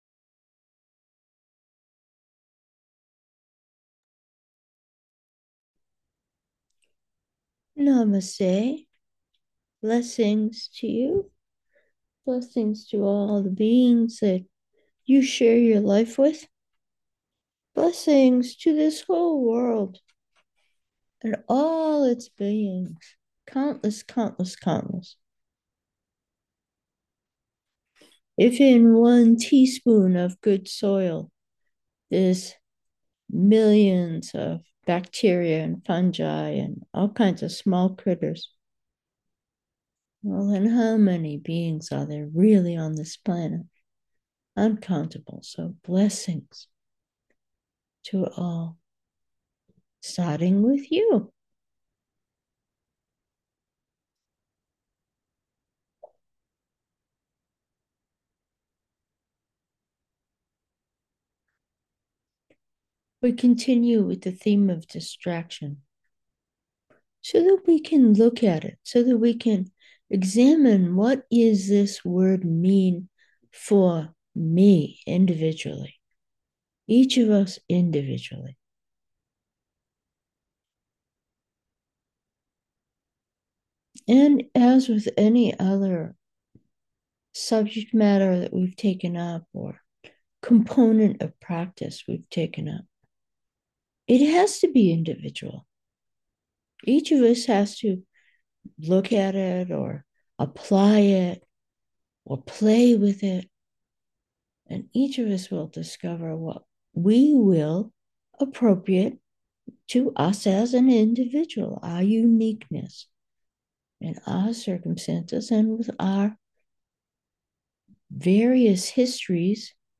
Meditation: individuation and distraction
This Tuesday’s preliminary contemplation was on the sense of personal uniqueness, i.e. identity, and its relationship with the particularity of the distractions we choose or are beguiled by. We, then, meditated.